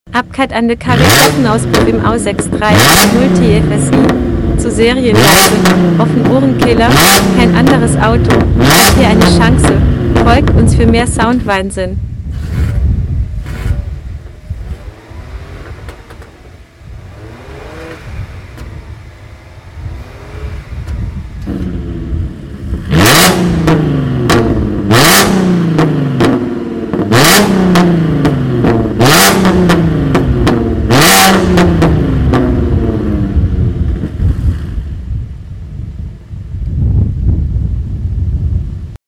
Undercover Klappenauspuff im A6 3.0